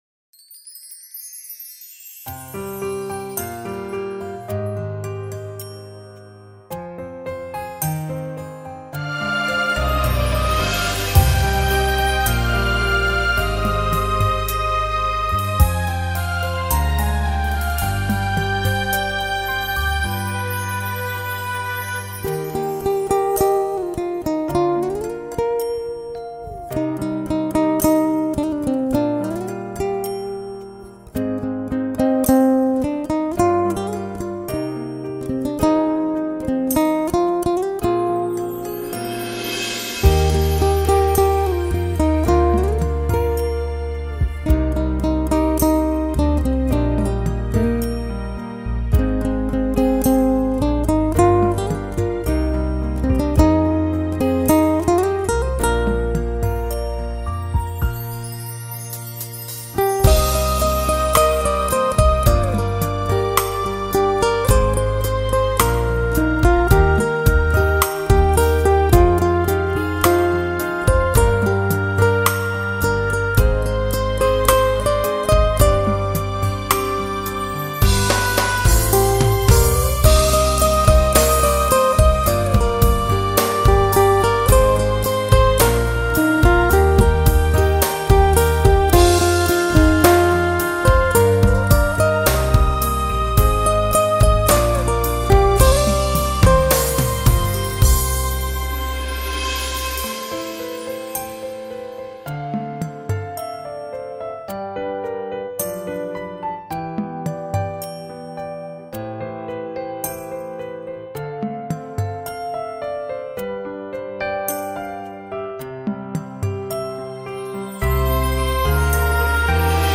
Nhạc Thư Giãn